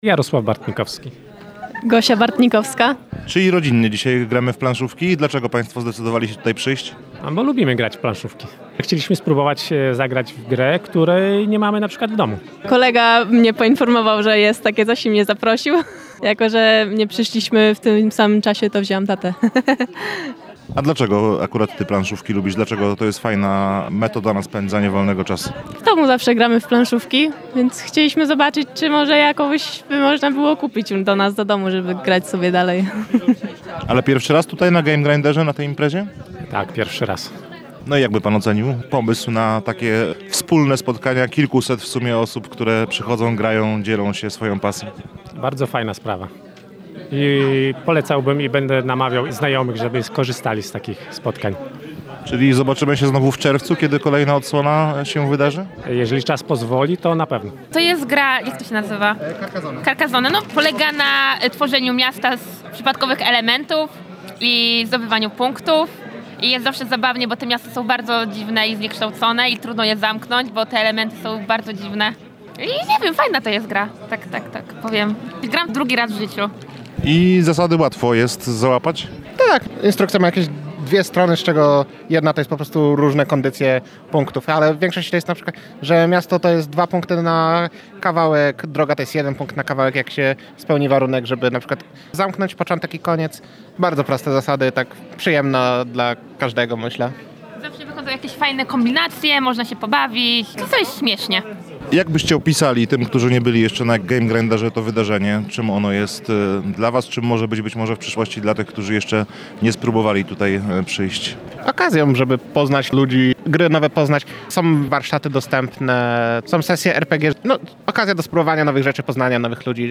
Rozmawialiśmy także z uczestnikami Gamegrindera. Posłuchajcie, dlaczego zdecydowali się zajrzeć w sobotę do Biblioteki UWM!
0202-MA-Gamegrinder-uczestnicy.mp3